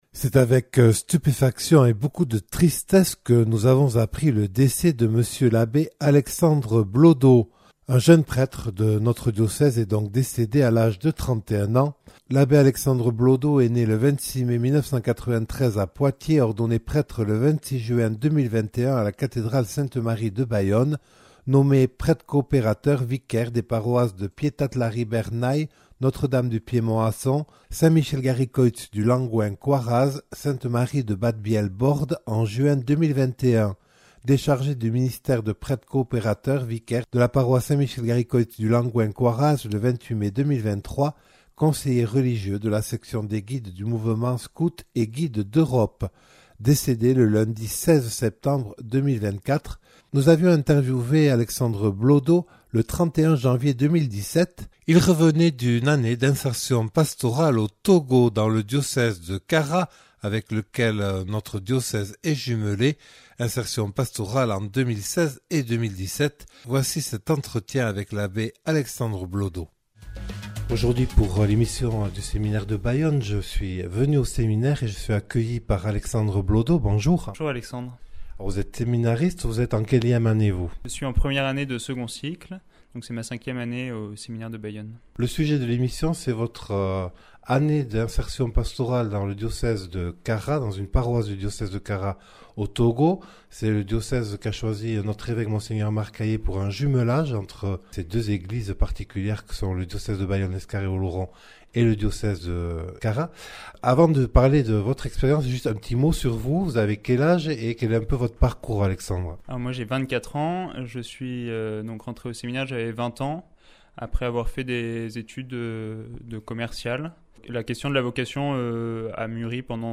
Rediffusion d’un entretien réalisé le 31 janvier 2017 au retour de son année d’insertion pastorale dans le diocèse de Kara au Togo.